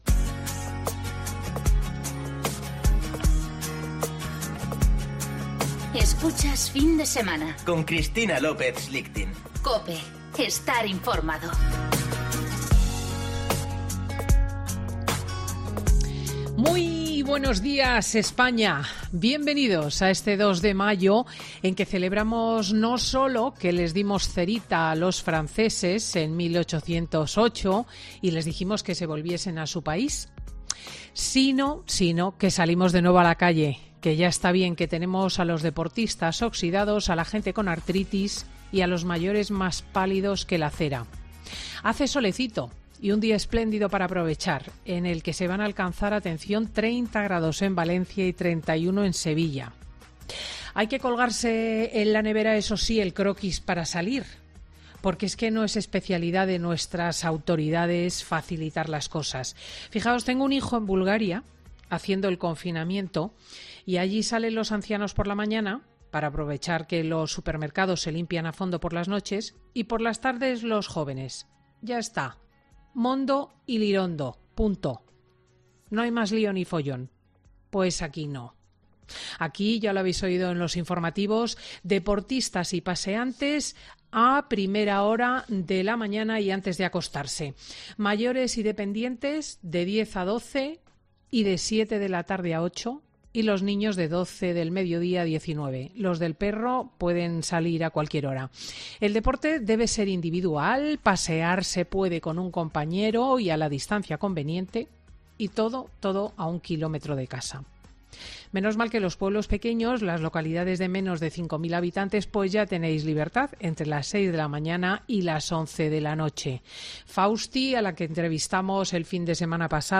AUDIO: La presentadora de 'Fin de Semana' analiza la propuesta del partido de Iglesias de la 'Tasa Covid' a los ricos